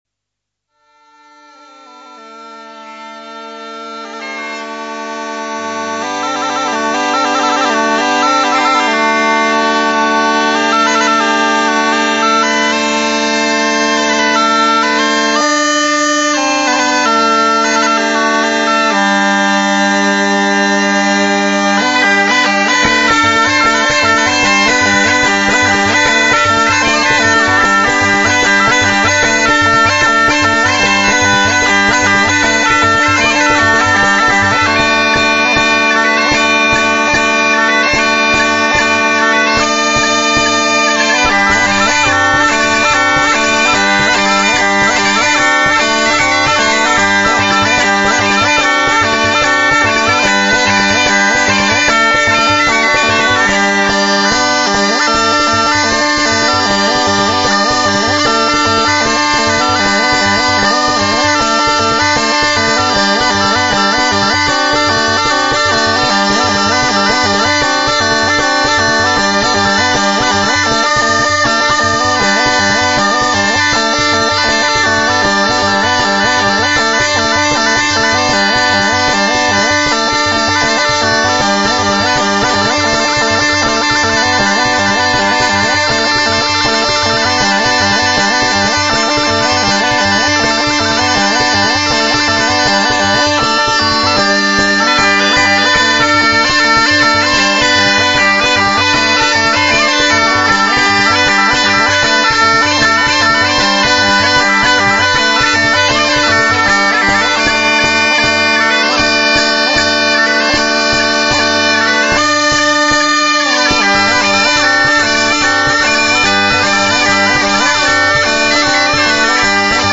Musica